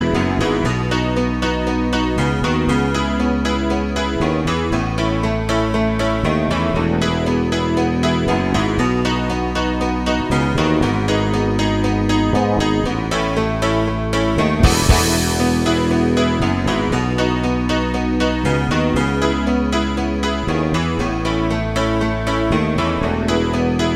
No Guitars With Backing Vocals Rock 4:26 Buy £1.50